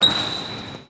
Chirrido de una zapatilla deslizándose por el parquet
zapato parqué chirrido